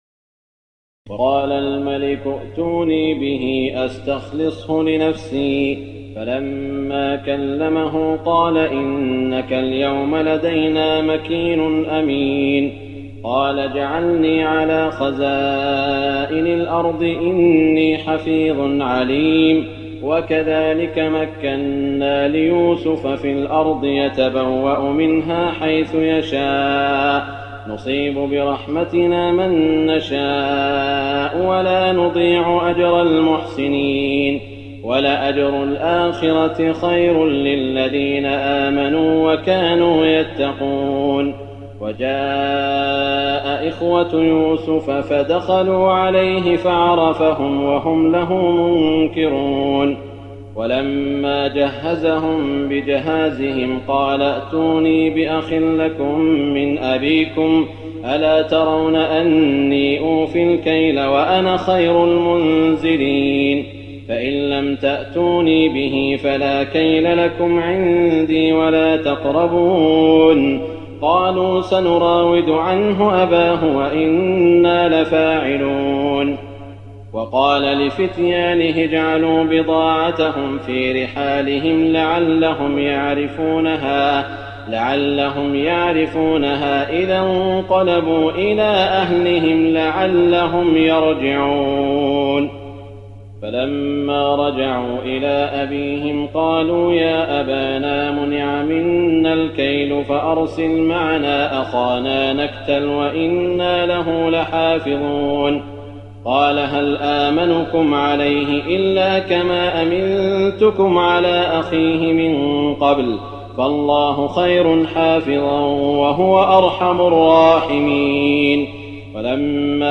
تراويح الليلة الثانية عشر رمضان 1419هـ من سورتي يوسف (54-111) و الرعد (1-18) Taraweeh 12 st night Ramadan 1419H from Surah Yusuf and Ar-Ra'd > تراويح الحرم المكي عام 1419 🕋 > التراويح - تلاوات الحرمين